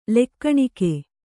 ♪ lekkaṇike